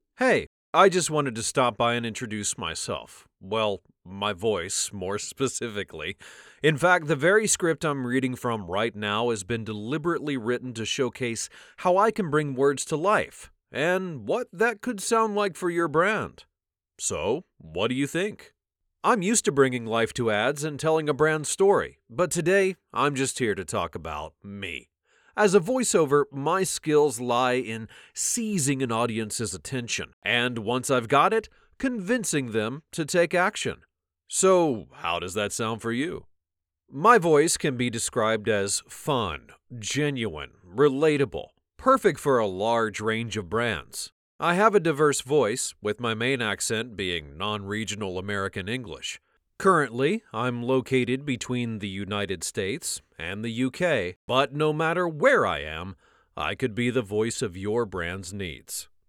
English (American)
Deep, Natural, Friendly, Warm, Corporate
Audio guide